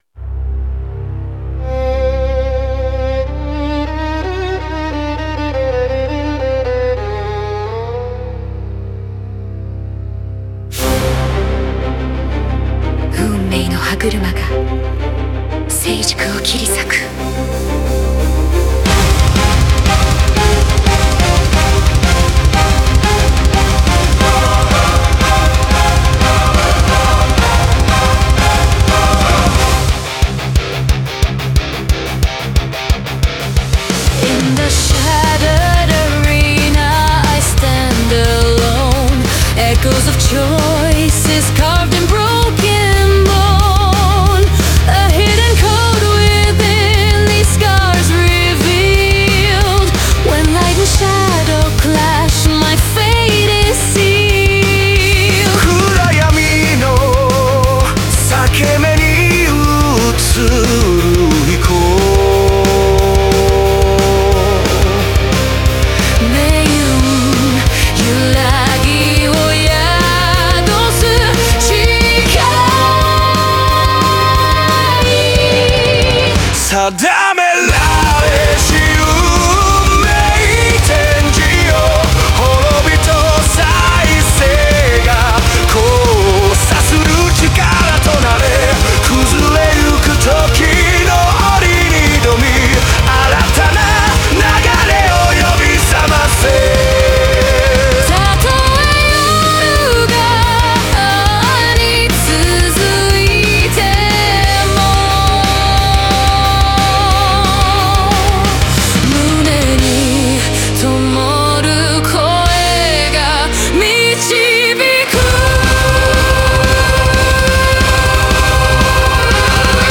Melodic Power Metal